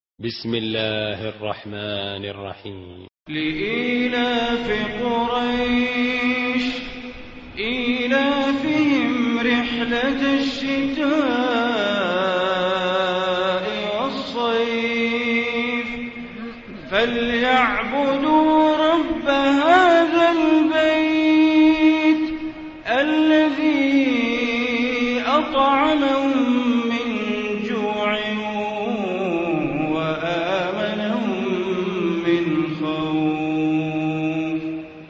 Surah Quraish Recitation by Sheikh Bandar Baleela
106-surah-quraish.mp3